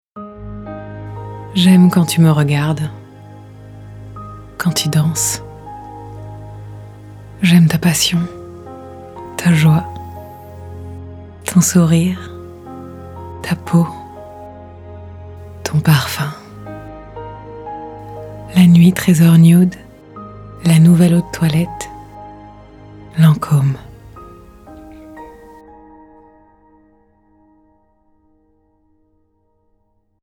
Voix off
25 - 60 ans - Mezzo-soprano